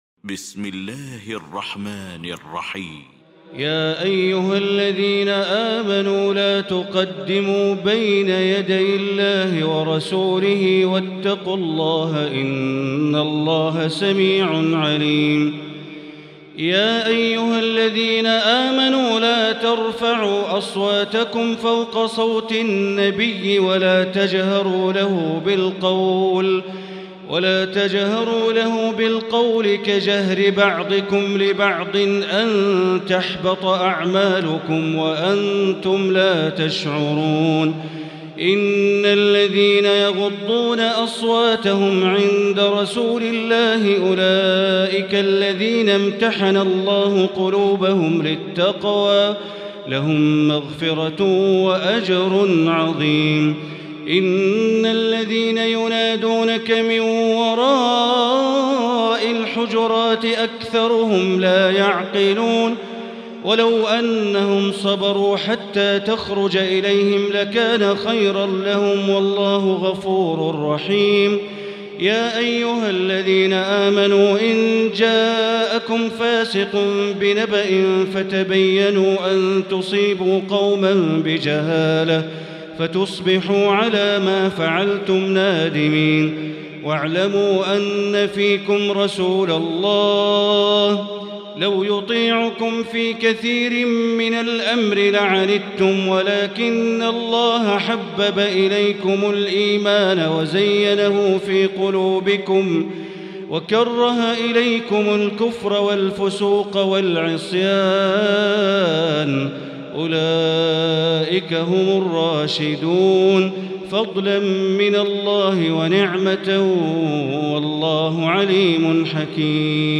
المكان: المسجد الحرام الشيخ: فضيلة الشيخ عبدالله الجهني فضيلة الشيخ عبدالله الجهني الحجرات